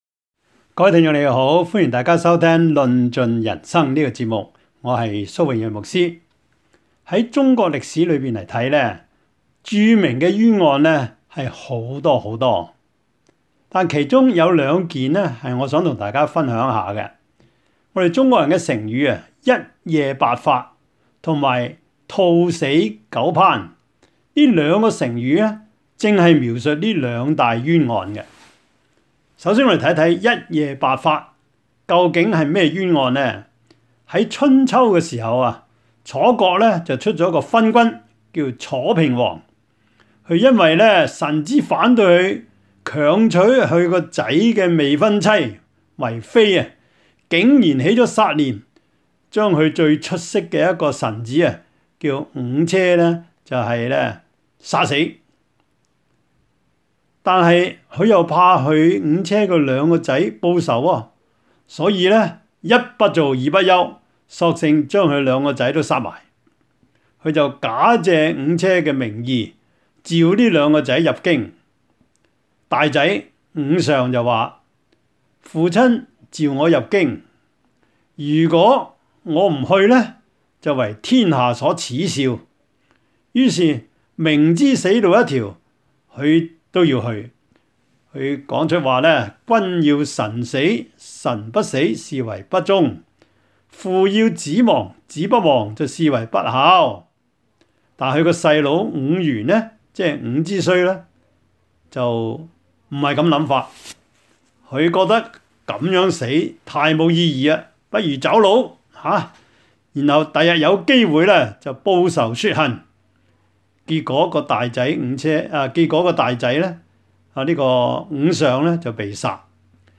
2020 Radio Talk 論盡人生